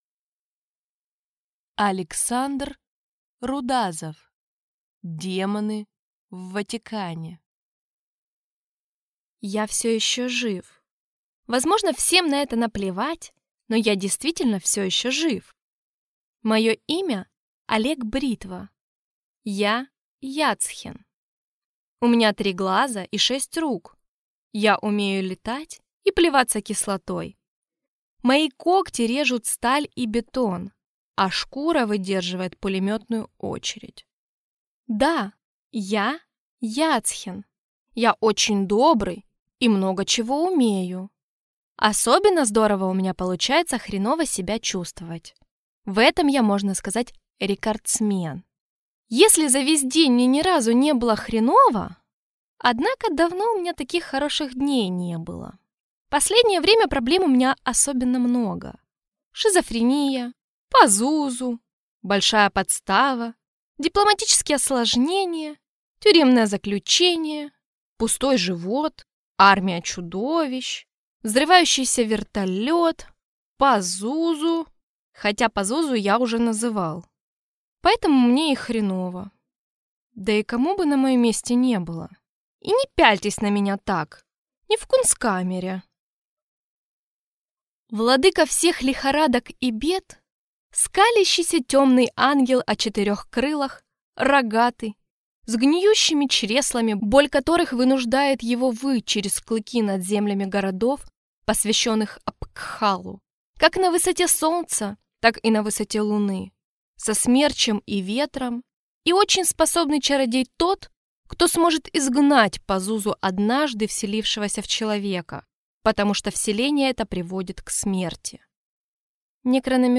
Аудиокнига Демоны в Ватикане | Библиотека аудиокниг